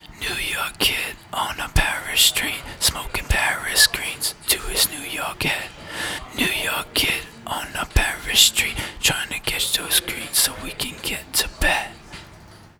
Track 11 - Vocal Whisper.wav